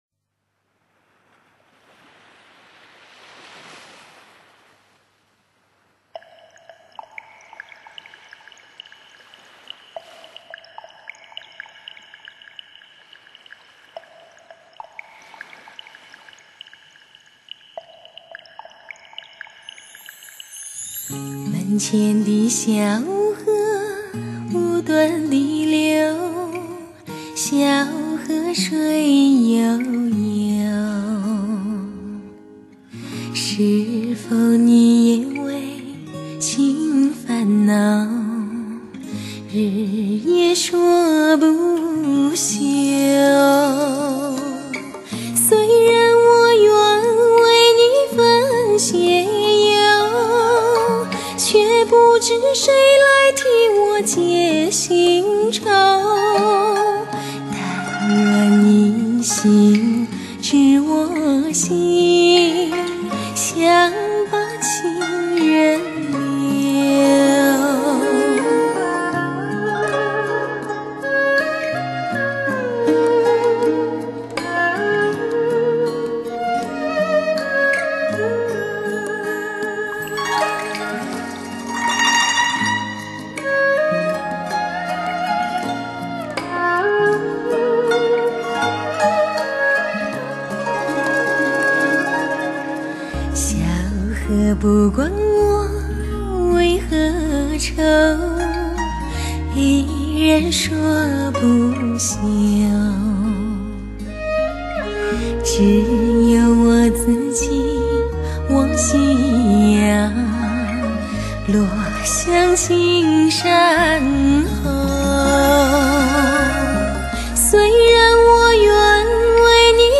最具女人味的魅力声线
最考验HIFI品质的录音典范。
声音很绵密细致,人也长的好甜美